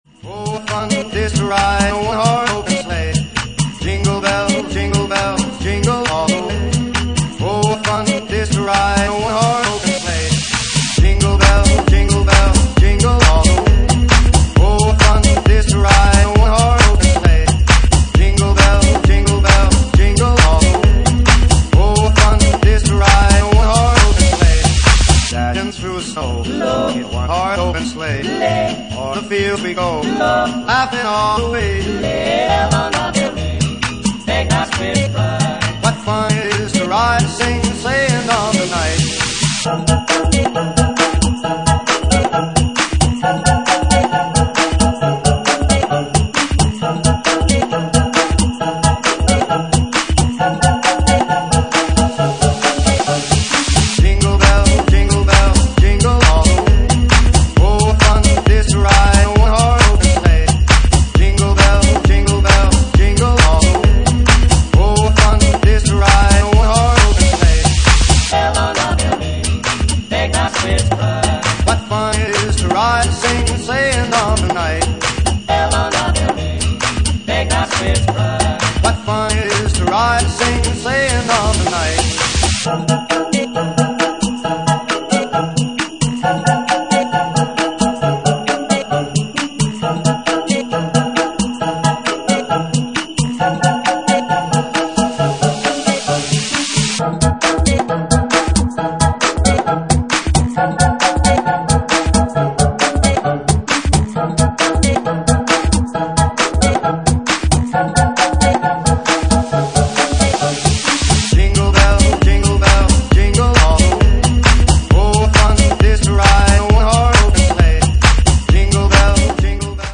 Genre:Jacking House
Jacking House at 67 bpm